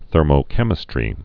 (thûrmō-kĕmĭ-strē)